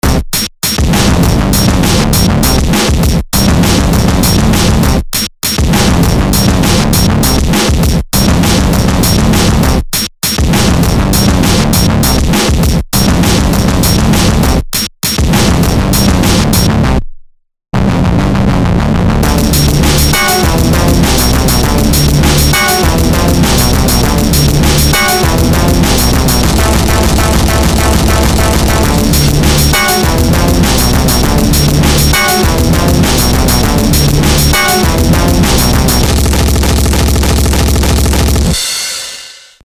breakcore, shitcore, noisecore, glitch,